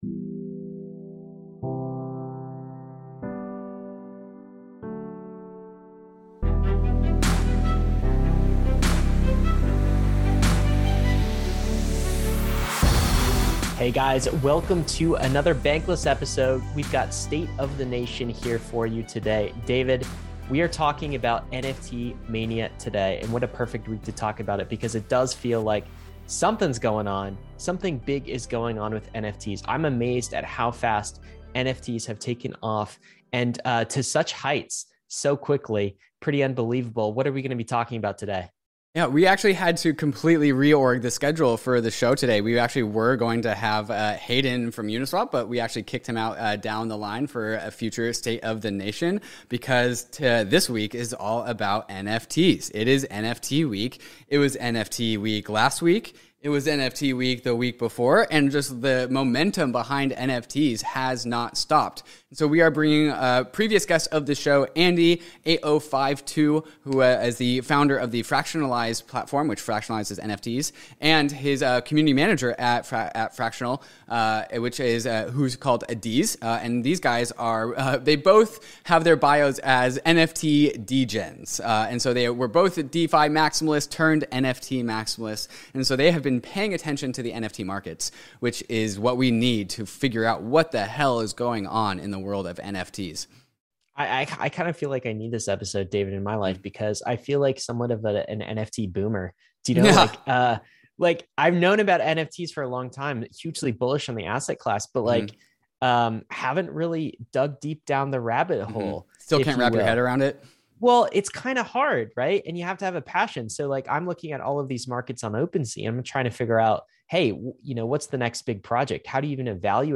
In this engaging discussion